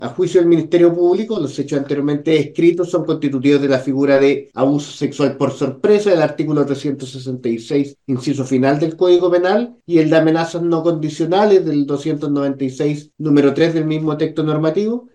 Fue formalizado por el fiscal, Miguel Ángel Velásquez y detalló los cargos que se le imputaron.